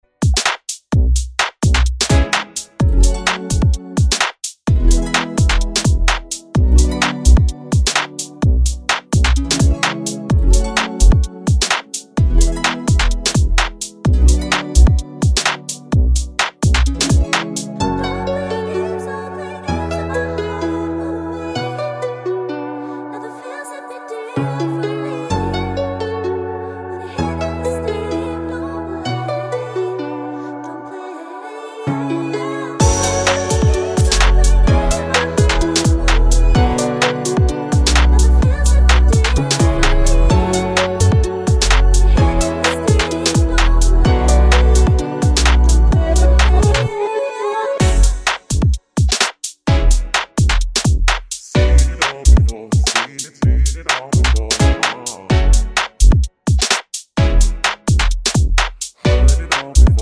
No High Harmony Pop (2020s) 3:10 Buy £1.50